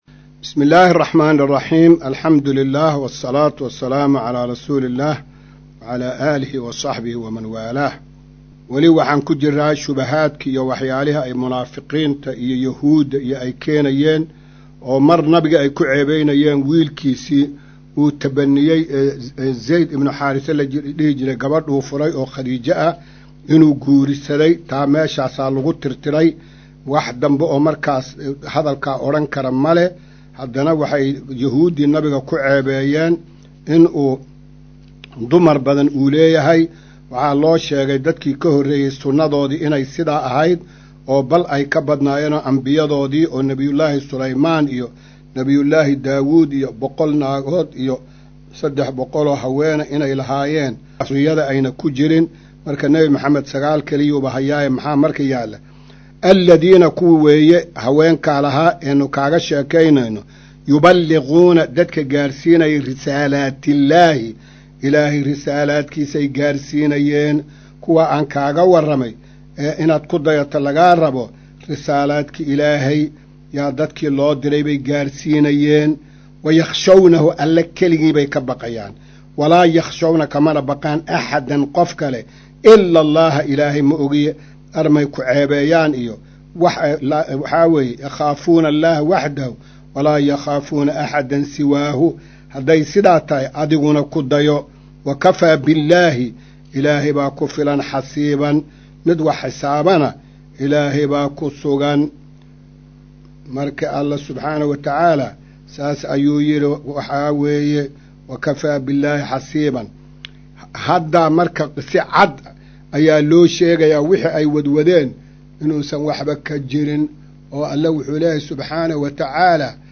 Maqal:- Casharka Tafsiirka Qur’aanka Idaacadda Himilo “Darsiga 200aad”